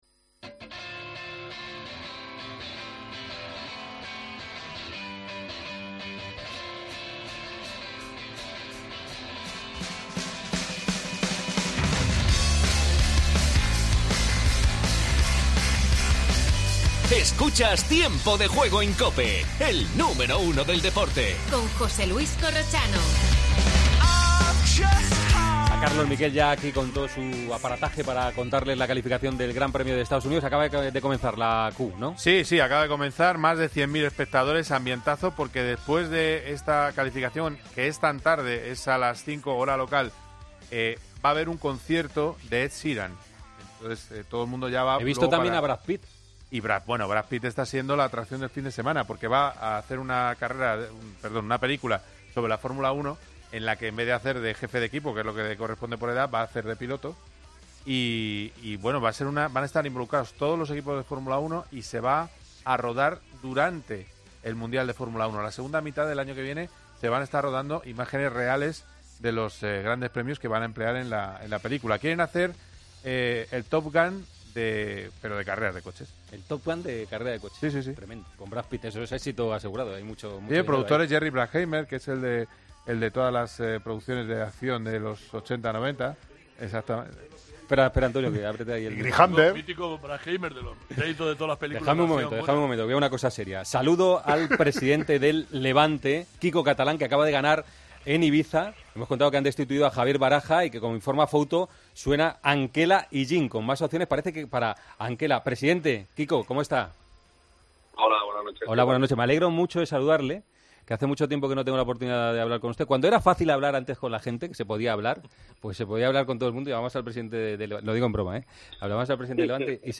Entrevista
En directo la clasificación del GP de Estados Unidos de fútbol. Previas del Barcelona-Athletic y del Atlético de Madrid-Betis.